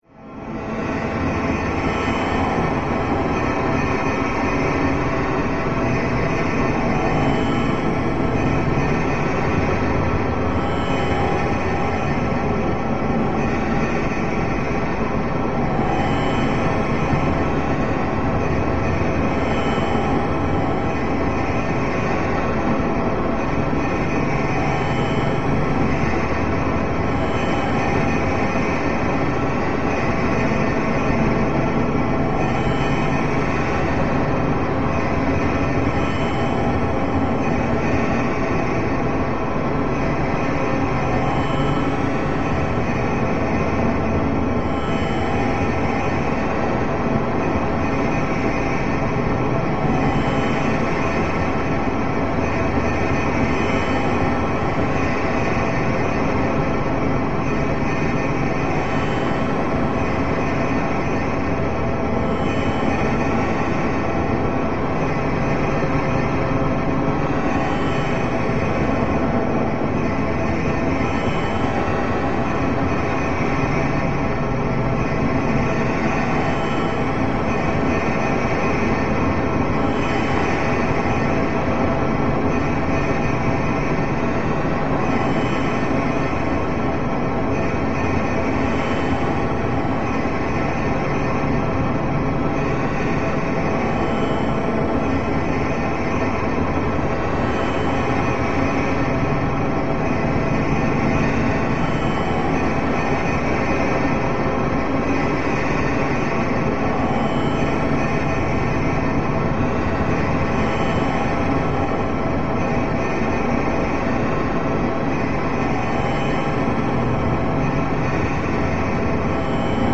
Thick Metallic Scrape Ambience Metallic, Scrape